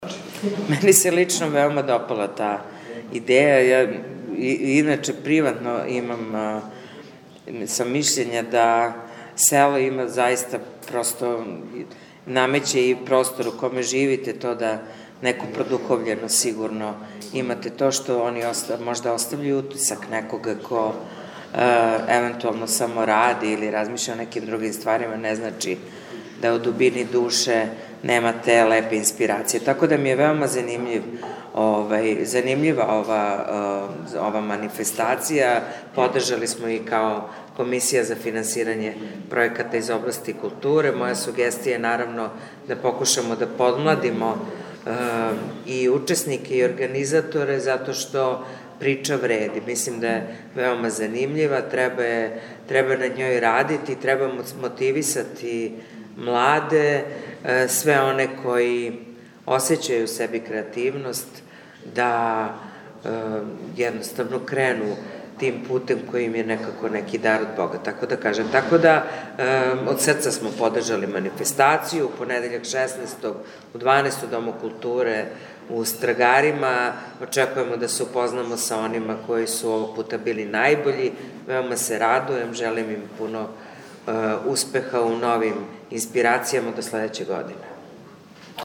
Завршна манифестација Фестивала књижевног и медијског стваралаштва на селу и о селу у Републици Србији “Моје село у причама и песмама“ ове године одржава се од 12 сати у Дому културе у Страгарима. На конференцији за медије, која је одржана у петак 13. децембра у Свечаном салону Зграде града, предстаљен је програм седмог издања овог фестивала.
Ана Петровић Јелић, чланица Градског већа за културу и истакла да је Комисија за финансирање пројеката из области културе и ове године подржала манифестацију “Моје село у причама и песмама“ која има за циљ промоцију књижевног и медијског стваралаштва на селу и о селу: